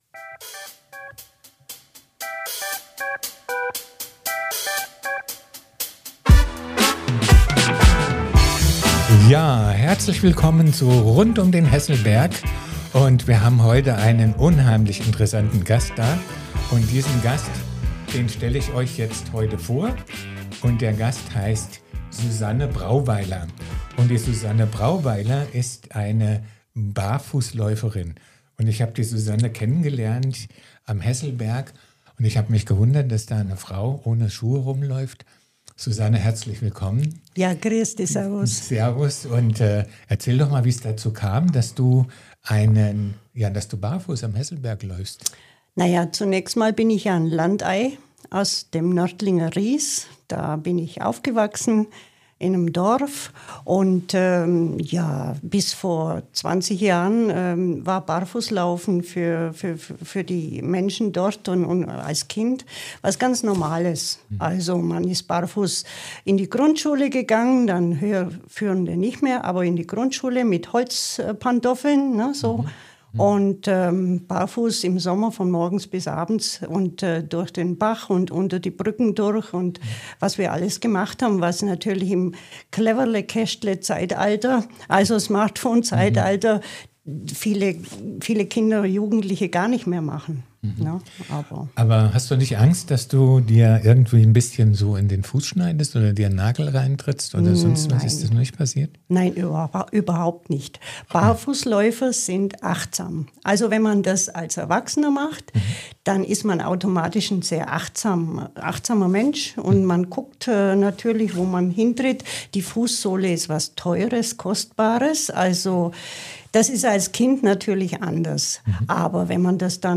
Freu dich auf ein ehrliches, persönliches Gespräch voller Inspiration und Lebensfreude!